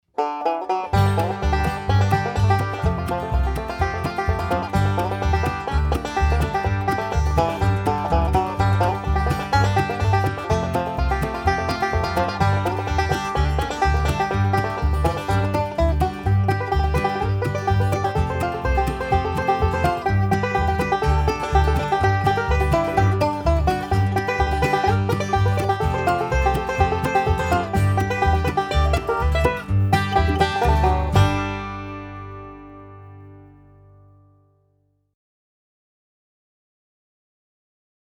DIGITAL SHEET MUSIC - 5-STRING BANJO SOLO
Three-finger "Scruggs style"
learning speed and performing speed